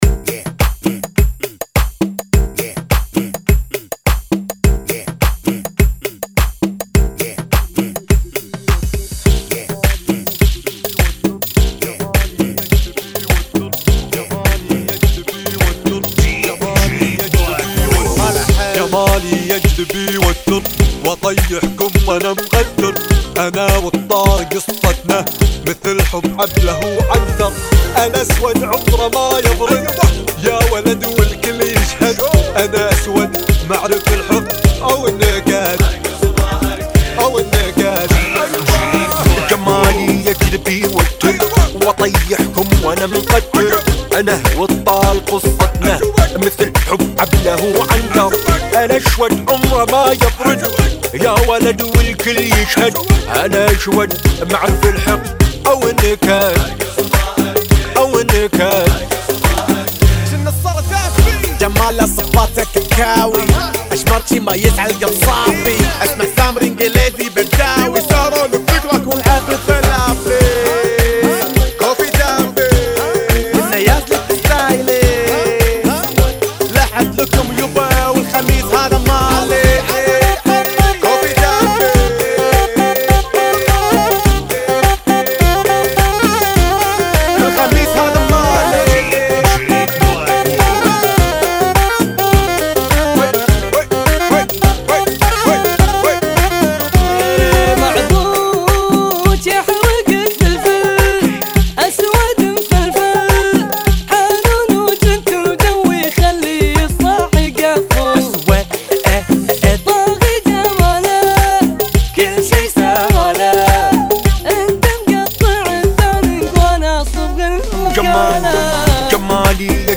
104 Bom